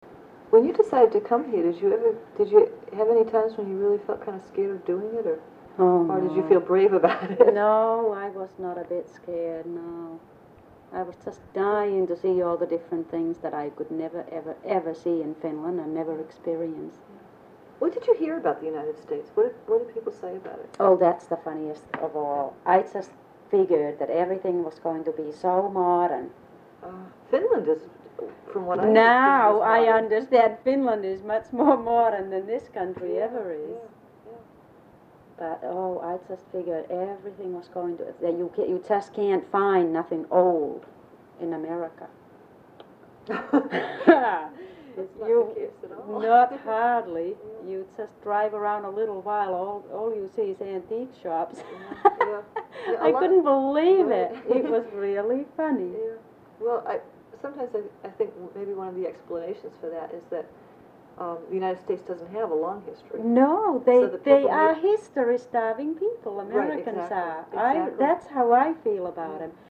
Oral History Overview
In 2003, most of the original sound cassettes were converted to compact discs by the Sawyer Free Library.